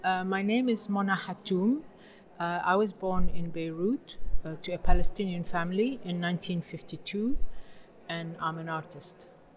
Captions English Add a one-line explanation of what this file represents Catalan Voice Intro Project de Mona Hatoum (editat per llevar soroll de fons)
Mona_Hatoum_-_voice.mp3